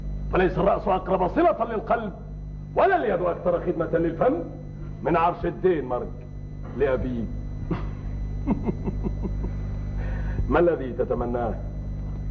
noisy_speech.mp3